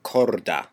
Ääntäminen
US : IPA : [ˈkɔrd]